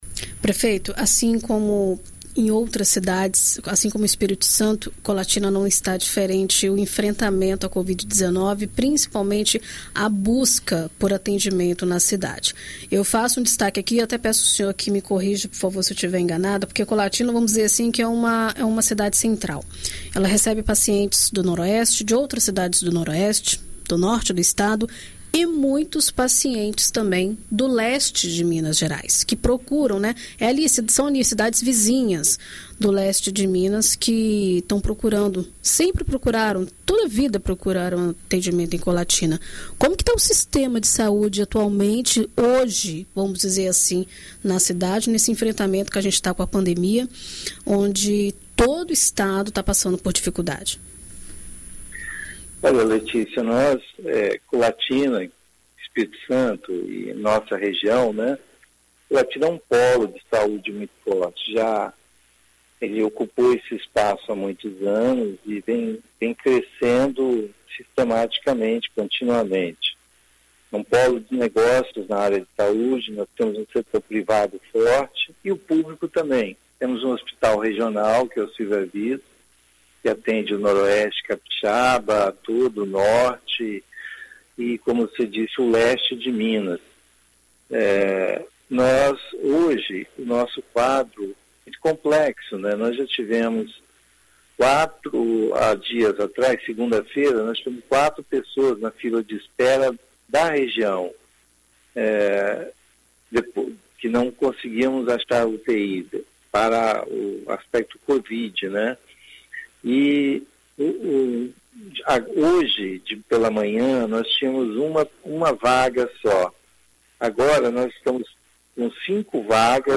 Em entrevista à BandNews FM, o prefeito detalha o decreto que restringe atividades durante a pandemia e fala sobre a situação nos leitos.